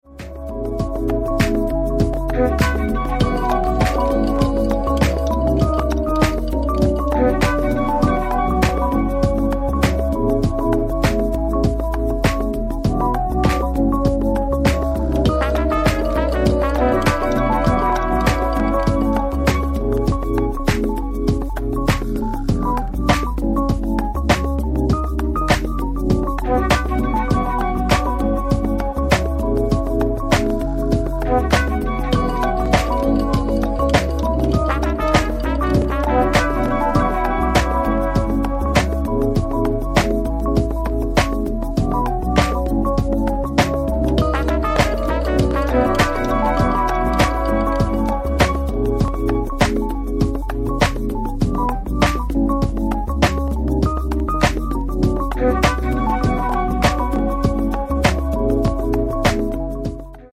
Sweet soulfull house tracks
House Soul Detroit Vintage